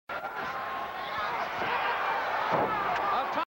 If you have ever watched a tape where the person recording the tape switched it from SP to EP mode or back and forth on an older VCR then you know what sound I am referring to -- the audio sounds slightly modulated -- it's kind of a chirp noise.
I'll include a few second clip of just the chirping noise from various parts of the the tape (it happens 4 times and the clip is replayed once, for a total of 8 instances) and then a slightly longer one where you can hear the noise just at the end.
audio blip2.mp3